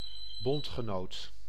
Ääntäminen
noun: IPA : /ˈæl.aɪ/ verb: IPA : /ə.ˈlaɪ/